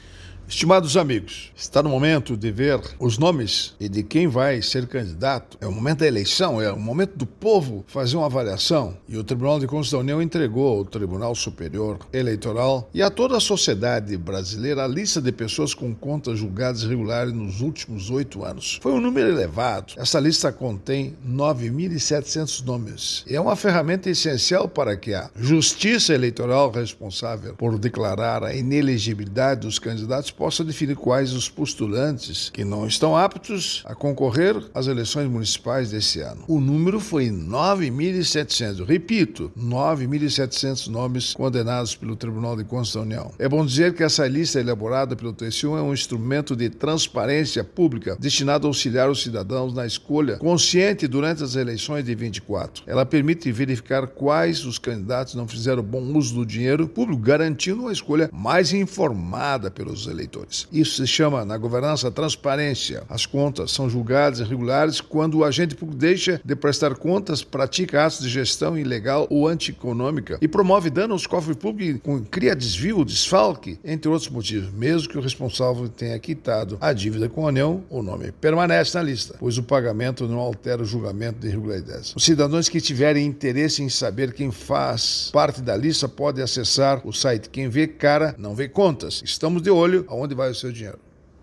É o assunto do comentário desta quarta-feira (28/08/24) do ministro Augusto Nardes (TCU), especialmente para OgazeteitO.